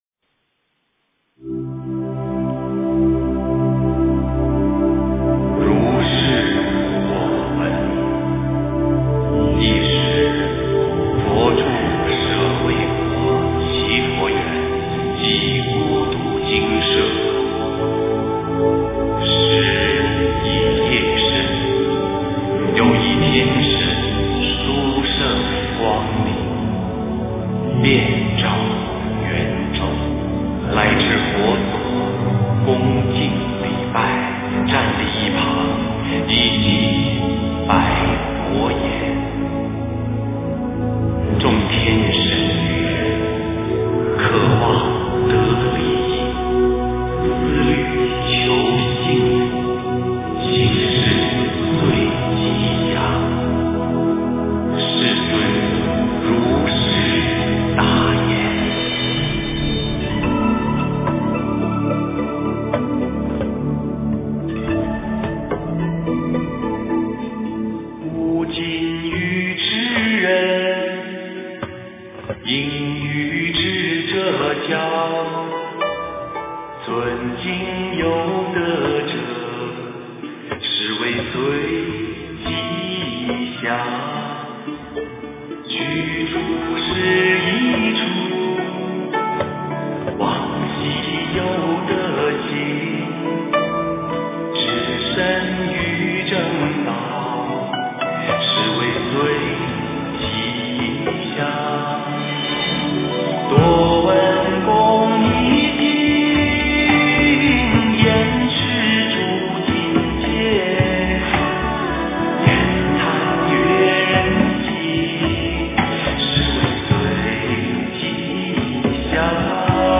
佛音 诵经 佛教音乐 返回列表 上一篇： 佛说八大人觉经 下一篇： 自性歌-六祖坛经择句 相关文章 楞伽经（一切法品第二之三） 楞伽经（一切法品第二之三）--未知...